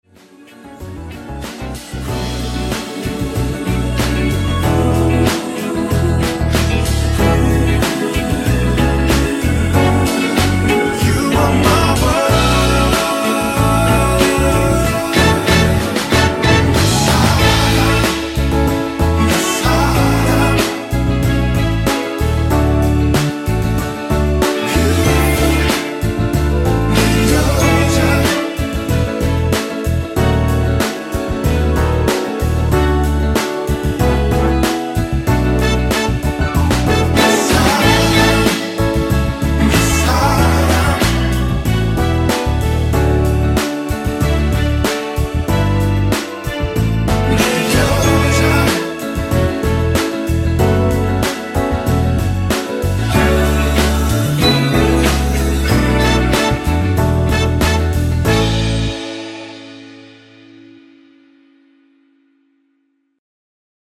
엔딩이 페이드 아웃이라 라이브 하기 좋게 원곡 3분 6초쯤에서 엔딩을 만들었습니다.(미리듣기 참조)
앞부분30초, 뒷부분30초씩 편집해서 올려 드리고 있습니다.
중간에 음이 끈어지고 다시 나오는 이유는